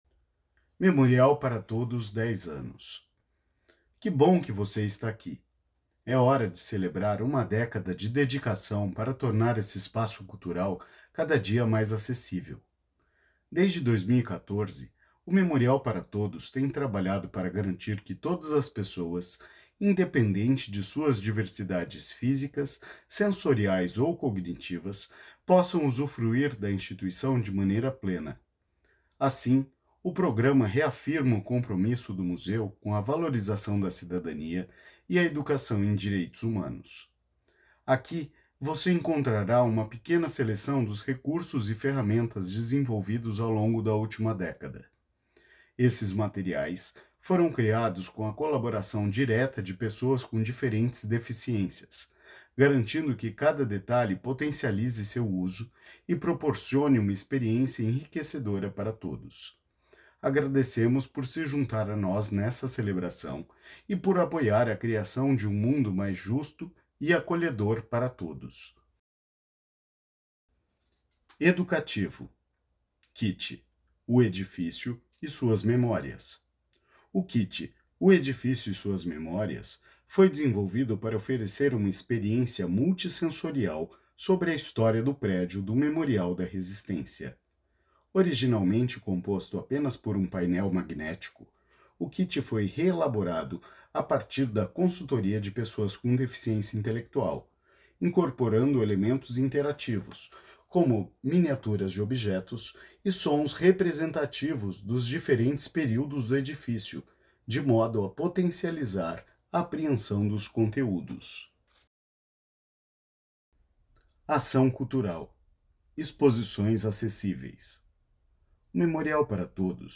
Audiodescricao_Memorial-ParaTodos-10-Anos.mp3